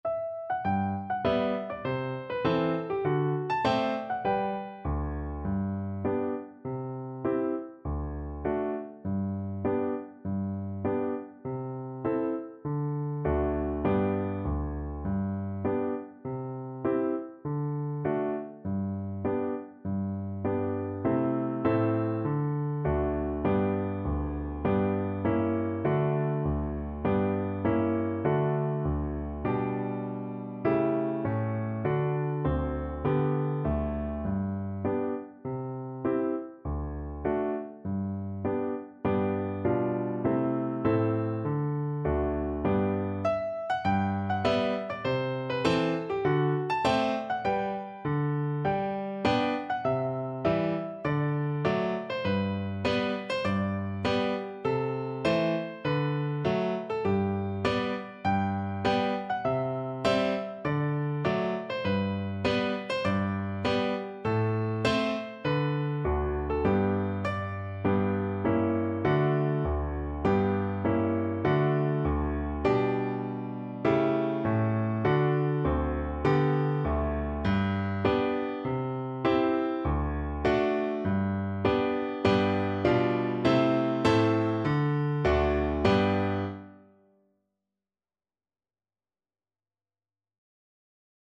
Play (or use space bar on your keyboard) Pause Music Playalong - Piano Accompaniment Playalong Band Accompaniment not yet available reset tempo print settings full screen
G major (Sounding Pitch) (View more G major Music for Recorder )
4/4 (View more 4/4 Music)